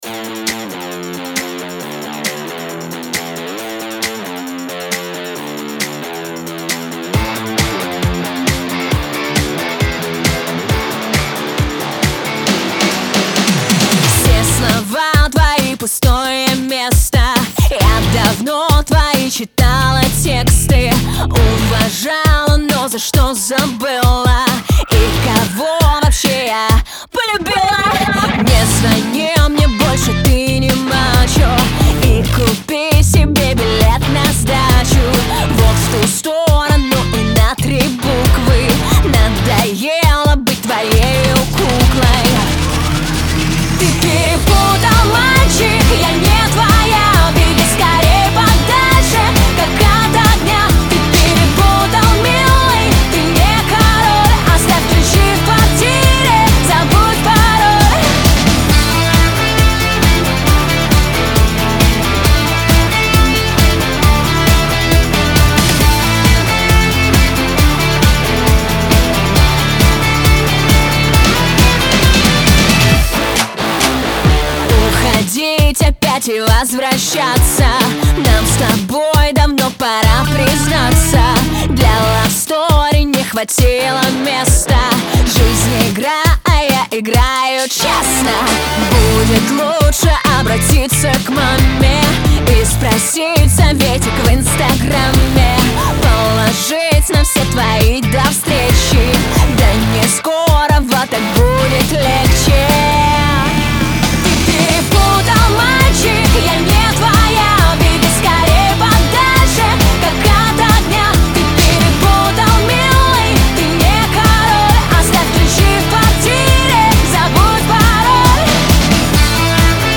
soulful вокал и запоминающиеся мелодии